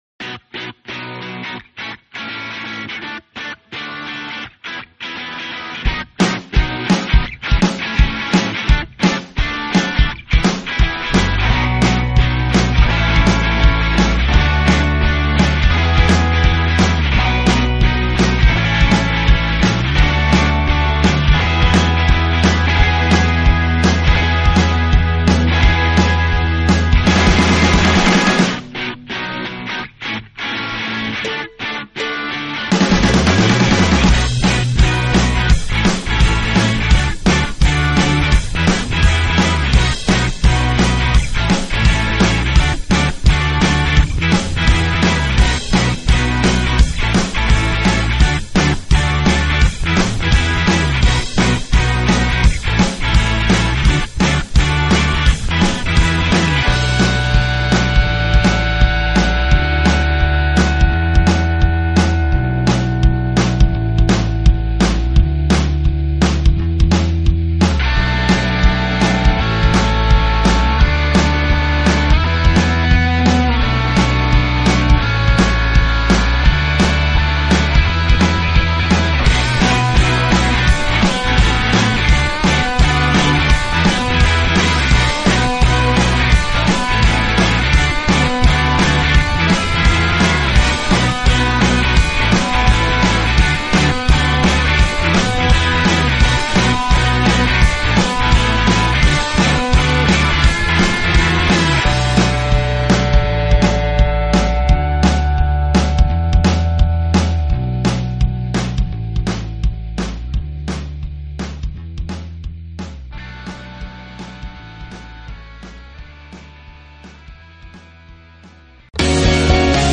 Rozmowy w Nocy to internetowy talk-show na żywo z udziałem słuchaczy w środy 23.00. Jest to audycja o życiu ludziach i ich problemach. Nasze tematy to człowieczeństwo, polityka, równouprawnienie, choroby dzisiejszego społeczeństwa, krytyka instytucji kościelnych nie jest nam obca, nauka, Bóg, wiara, religia, nasze lęki.